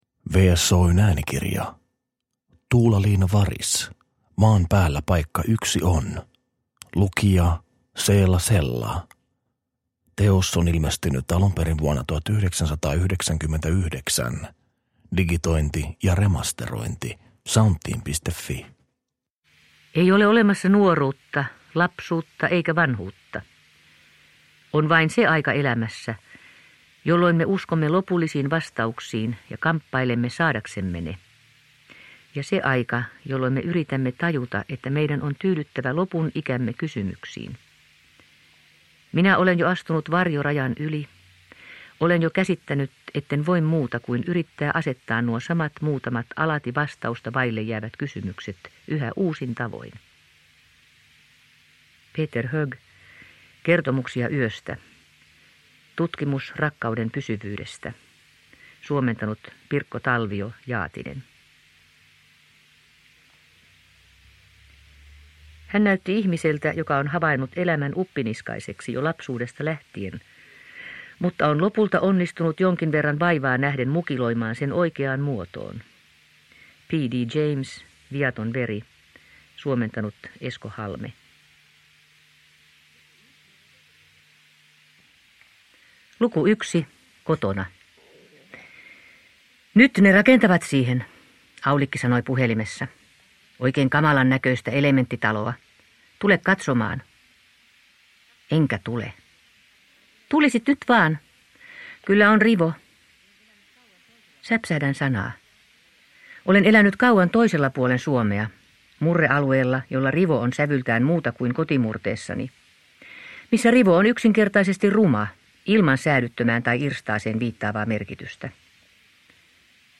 Maan päällä paikka yksi on – Ljudbok – Laddas ner
Uppläsare: Seela Sella